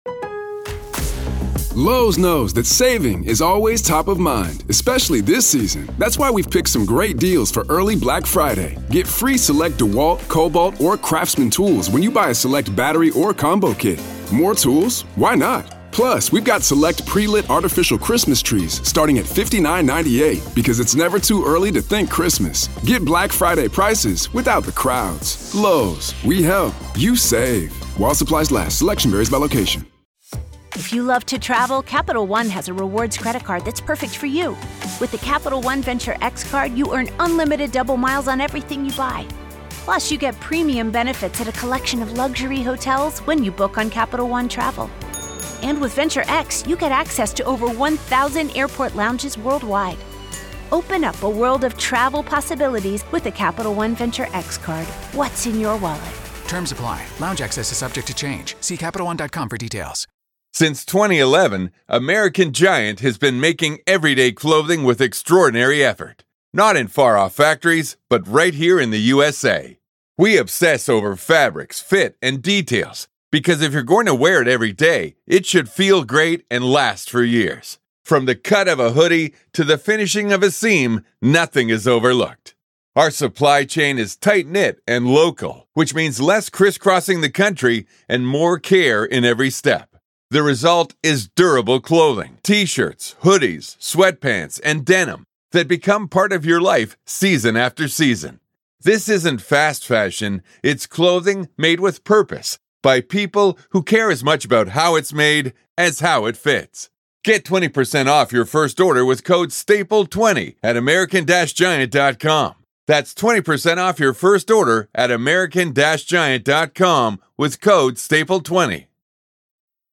The duo dissected the clues and potential evidence found at the suspect's home following an exhaustive 12-day investigation by law enforcement.